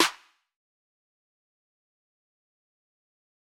Snares
JJSnares (9).wav